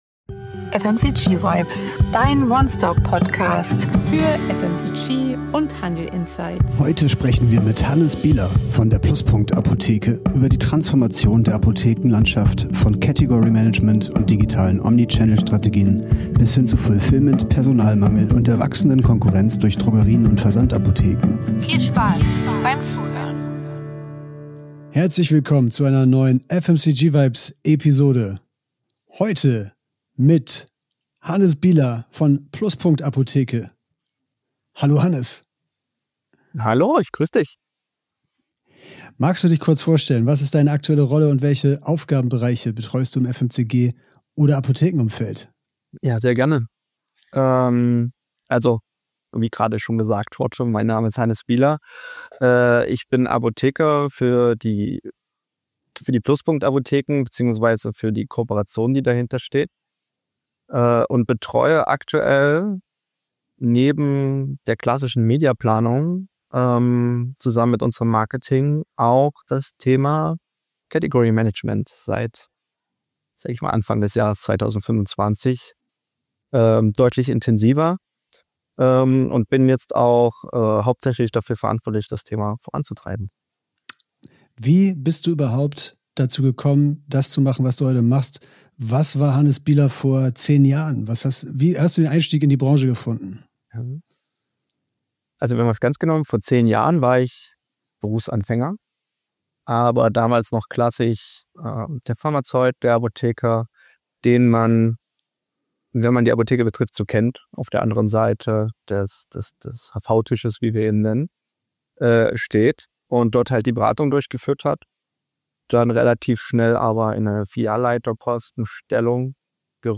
Expertentalk